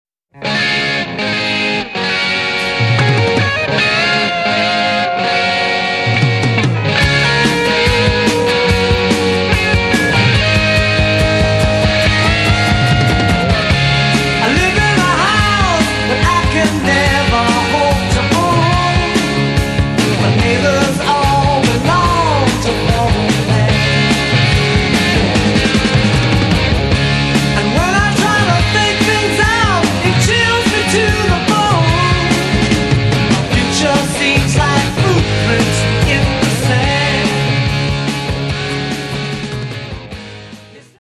ドラム
ギター
ベース